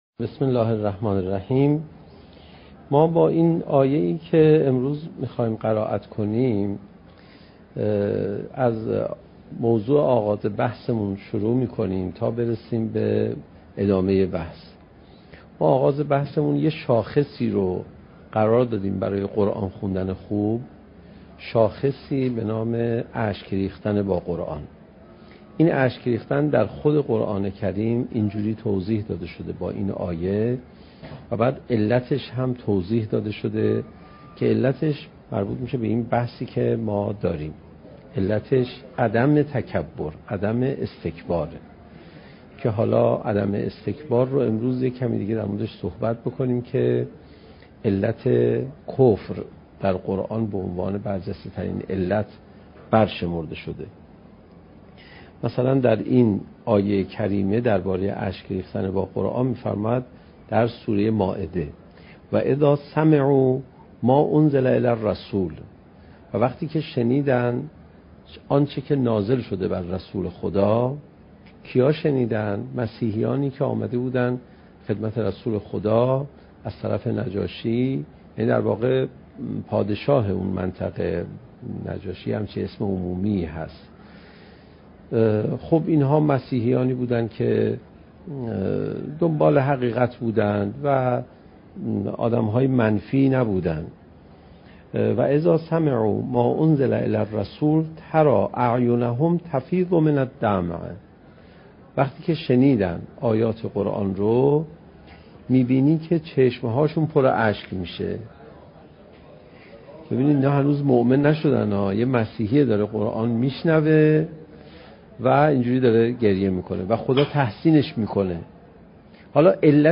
سخنرانی حجت الاسلام علیرضا پناهیان با موضوع "چگونه بهتر قرآن بخوانیم؟"؛ جلسه یازدهم: "شکستن حس تکبر"